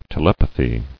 [te·lep·a·thy]